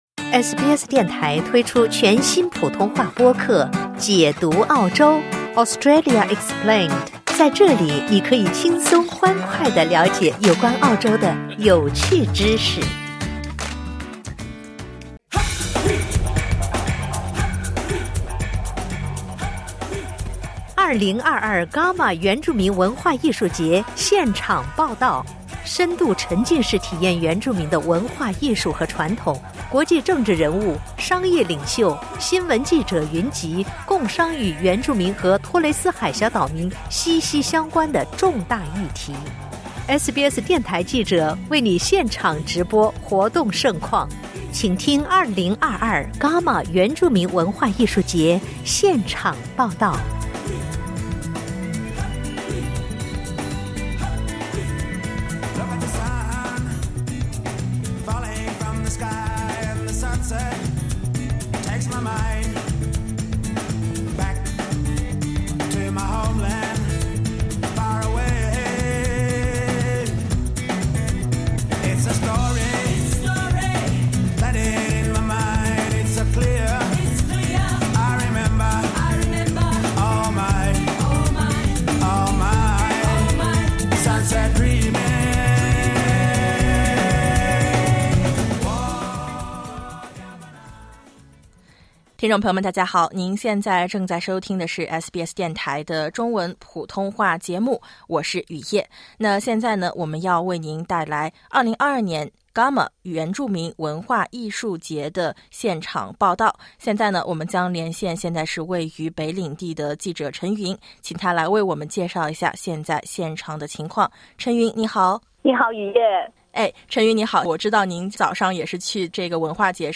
【伽马文化艺术节】特派记者现场报道